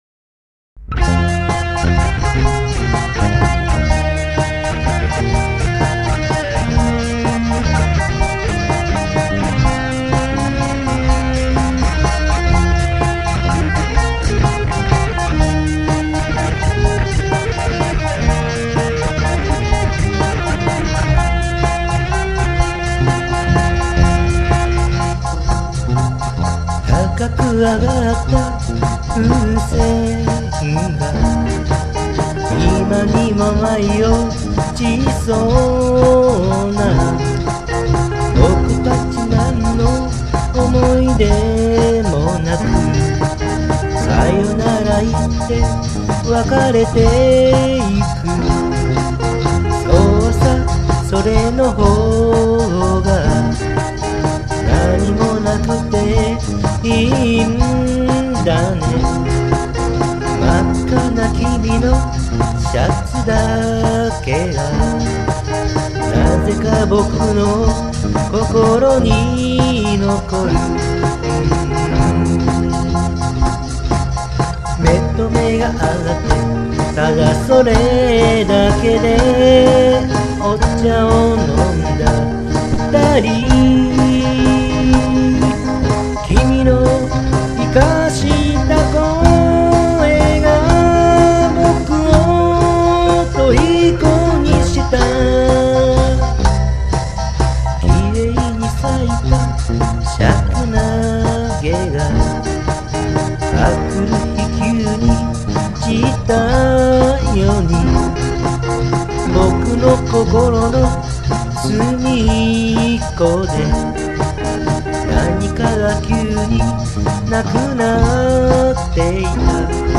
AG  Gibson
ドラム  Pistoncollage
総ての楽器にV-TONEっていうプリアンプを使ってます。
ベースは少し歪んじゃって(;^_^A あせあせ
今回エレキギターは歌の時はフロントのダンカン
リードソロはリアのディマジオって使い分けてます。
今回は歪み系のエフェクトをＭＵＦＦを使ってます。
普段あまりディレイを使わないんですけど今回はアナログディレイでセットしてます。
この曲は３/４拍子の曲なんですけど、この「そうさそれの方が」の